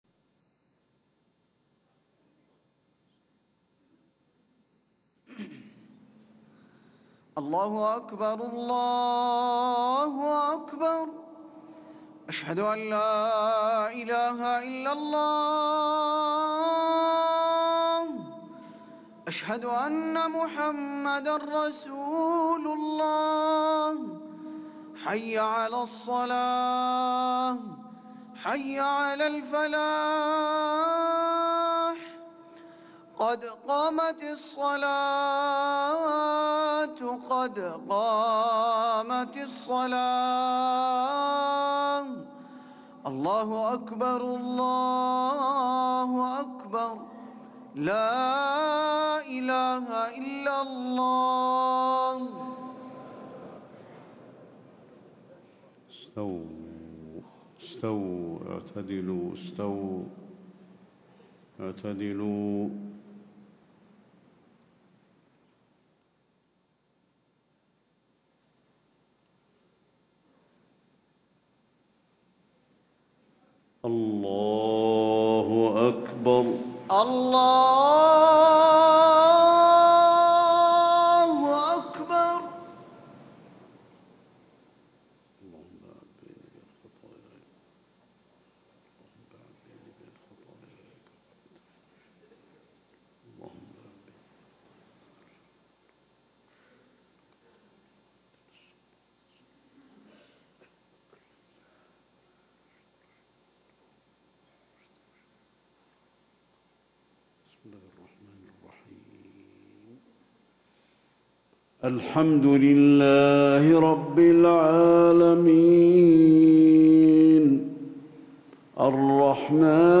صلاة الفجر 1 ذو القعدة 1437هـ سورة الذاريات > 1437 🕌 > الفروض - تلاوات الحرمين